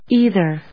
ei・ther /íːðɚ, άɪðɚάɪðə, íːðə/